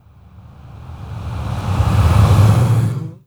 SIGHS 1REV-L.wav